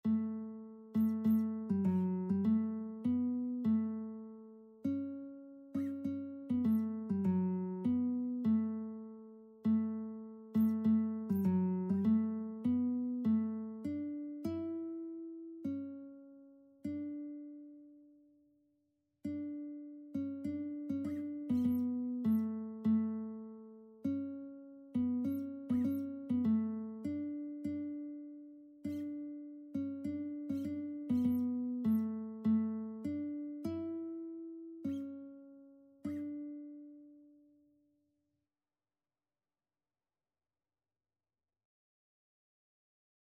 Christian Christian Lead Sheets Sheet Music At Calvary
4/4 (View more 4/4 Music)
D major (Sounding Pitch) (View more D major Music for Lead Sheets )
Classical (View more Classical Lead Sheets Music)